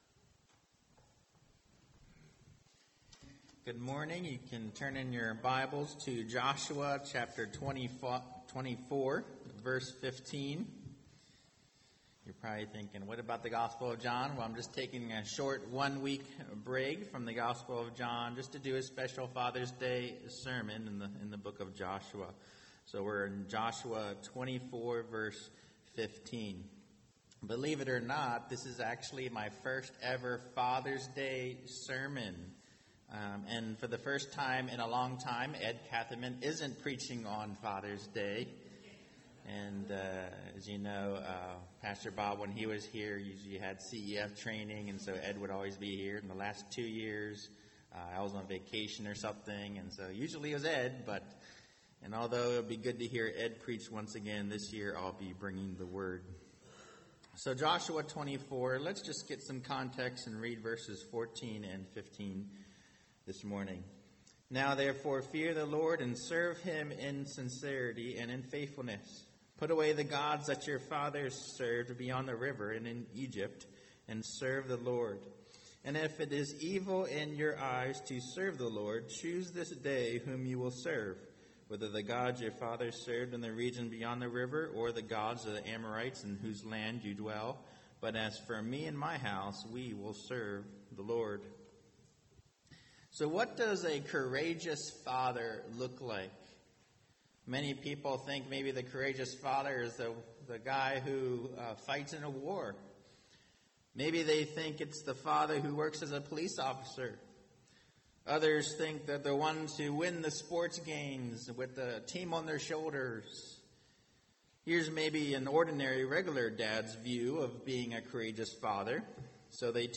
Non-Series Sermon